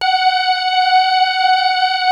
Index of /90_sSampleCDs/Wizoo - Powered Wave/PPG CHOIR